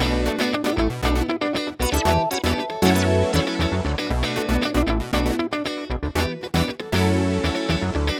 11 Backing PT1.wav